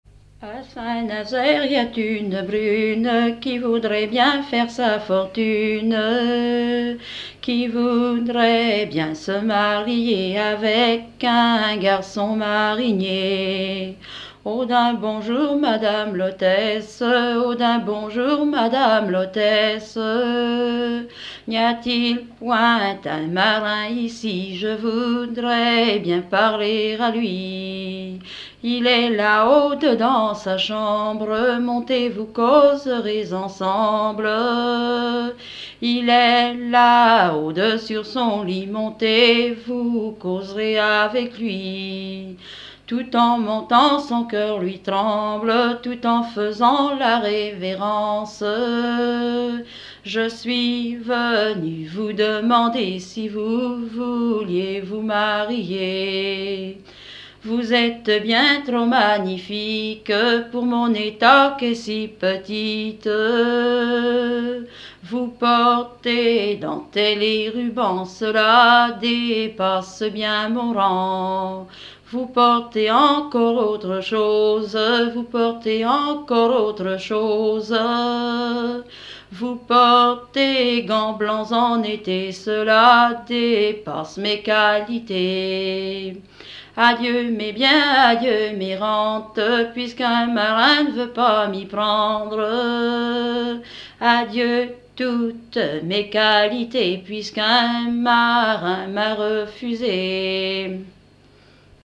danse : ronde
Pièce musicale inédite